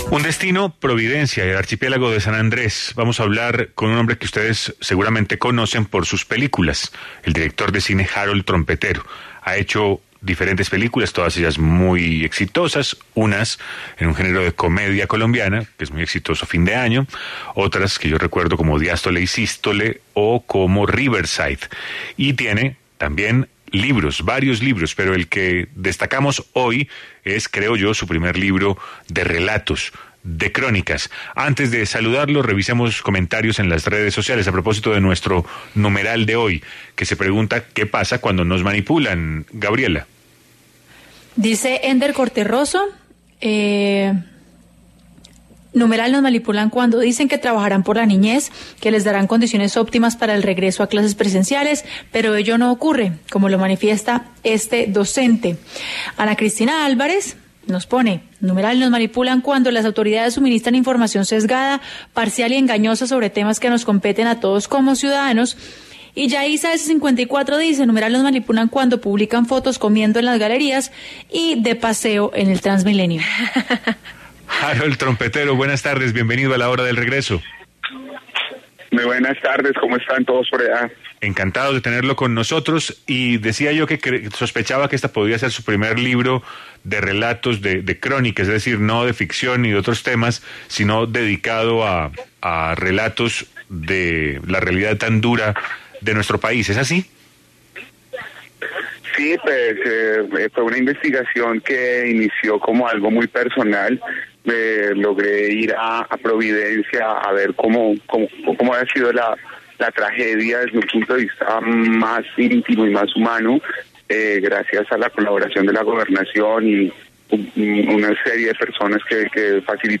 El director de cine conversó con La Hora del Regreso sobre esta nueva apuesta literaria en la que contará las historias de varias de las víctimas en Providencia.